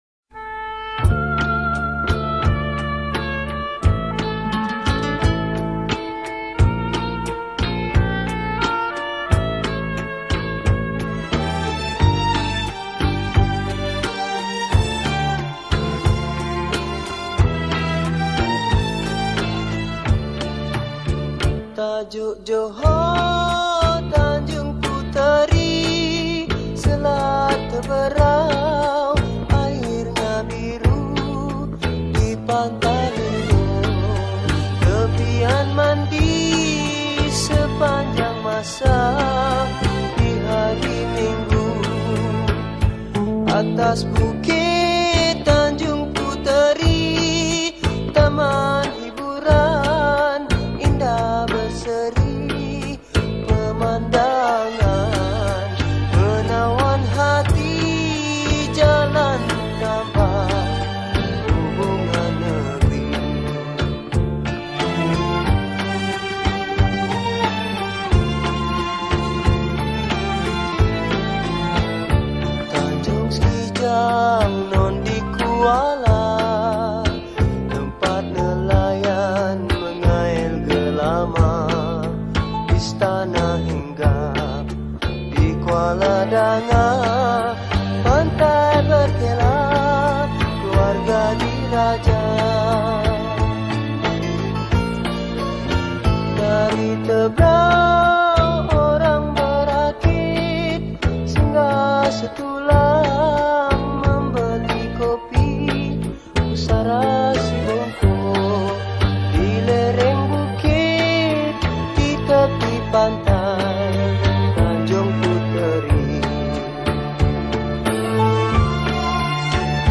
Malay Songs , Patriotic Songs
Skor Angklung